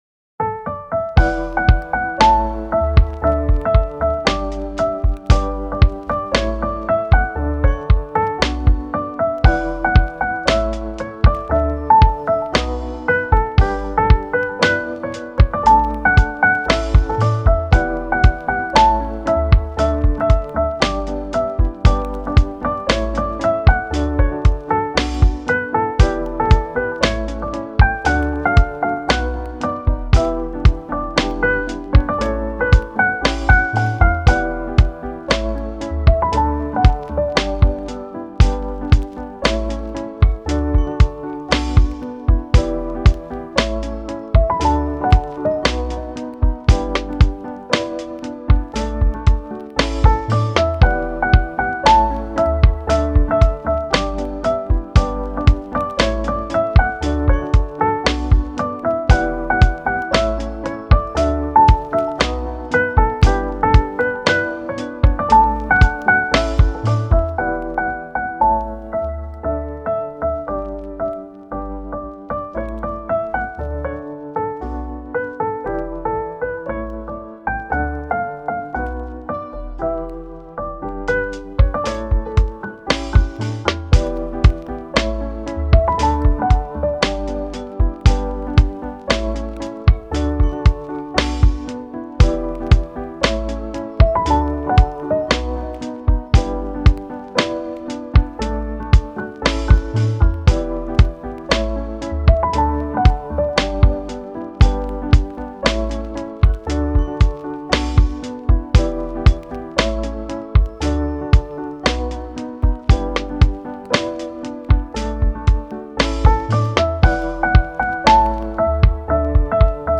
カフェミュージック
チル・穏やか